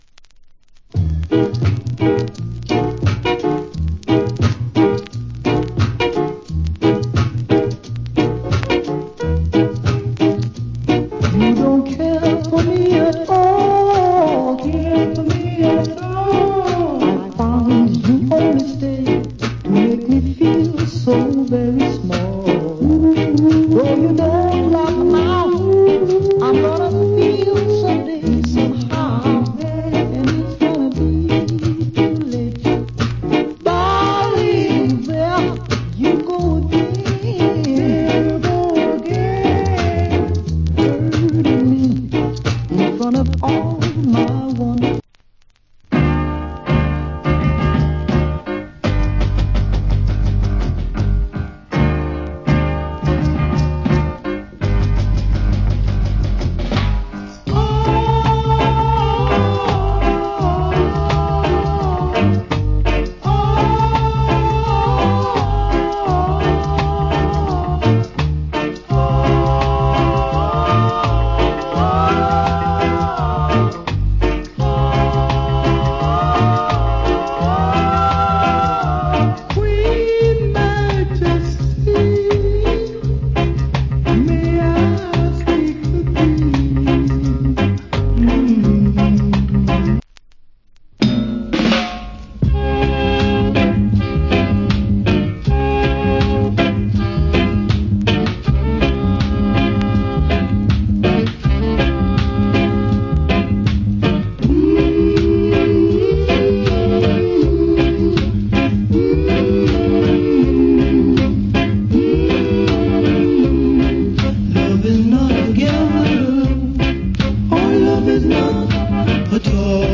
Nice Ska To Reggae